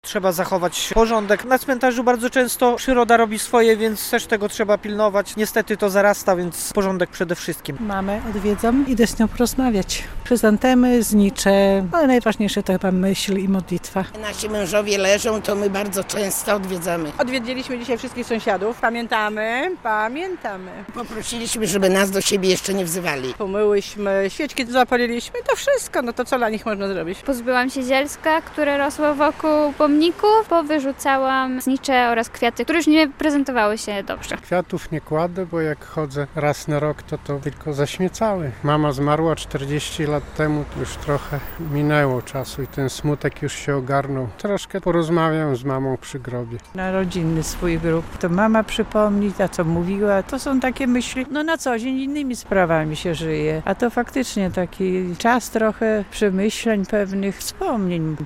To oznaka naszej pamięci i szacunku dla zmarłych - mówią białostoczanie, których spotkaliśmy na cmentarzach. Przed dniem Wszystkich Świętych na nekropoliach pojawia się coraz więcej osób, które sprzątają groby bliskich, zapalają znicze i wspominają zmarłych.
Porządki na grobach bliskich - relacja